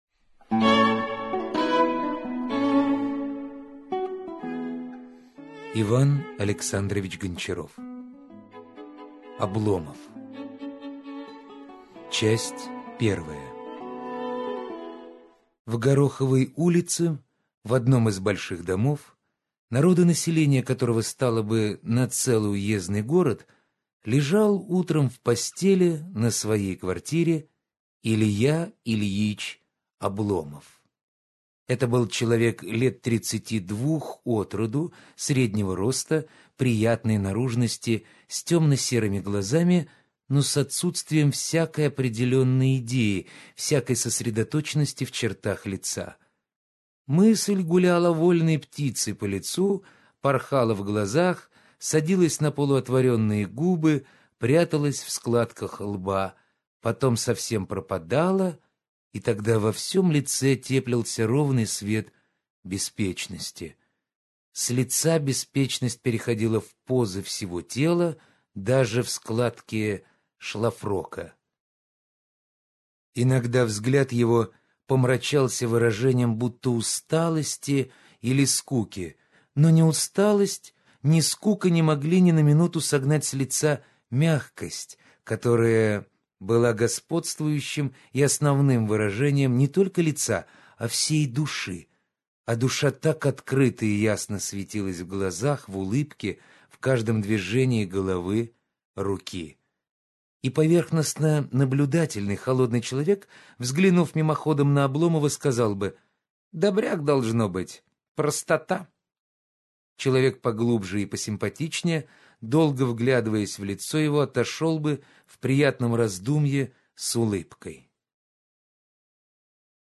Аудиокнига Обломов - купить, скачать и слушать онлайн | КнигоПоиск
Аудиокнига «Обломов» в интернет-магазине КнигоПоиск ✅ Классика в аудиоформате ✅ Скачать Обломов в mp3 или слушать онлайн